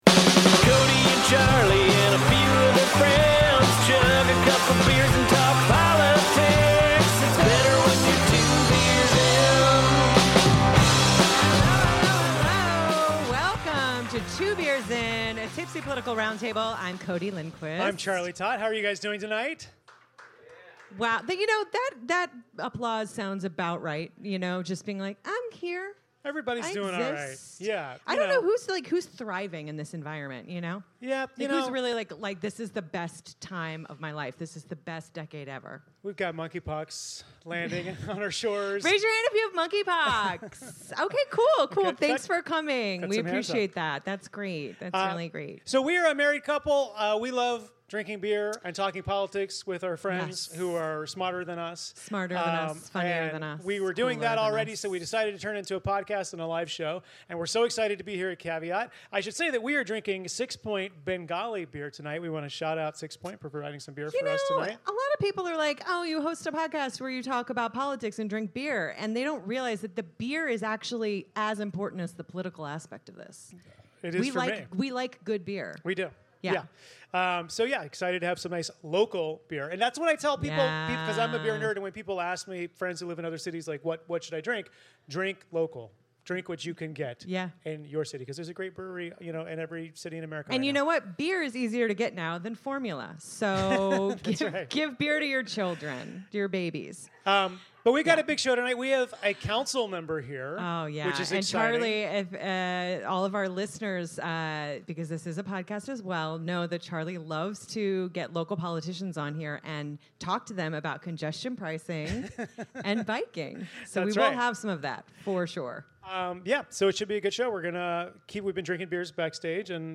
Recorded live at Caveat NYC